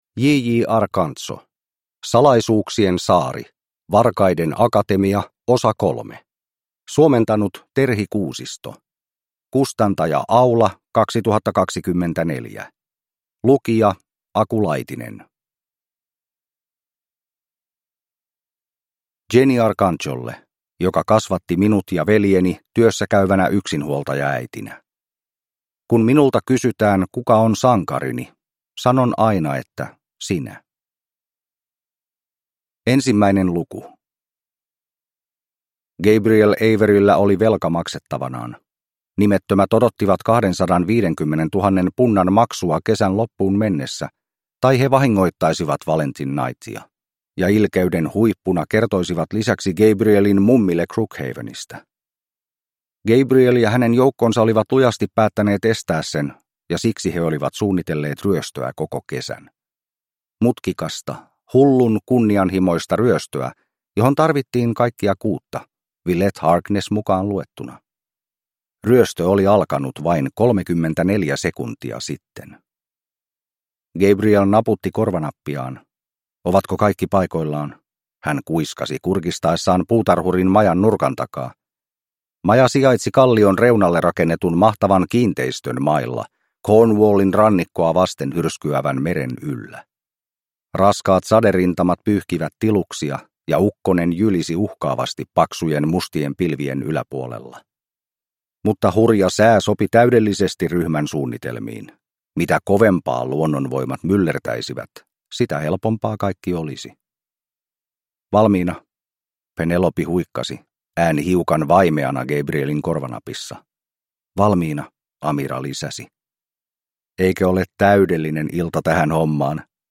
Salaisuuksien saari – Ljudbok